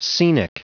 Prononciation du mot scenic en anglais (fichier audio)
Prononciation du mot : scenic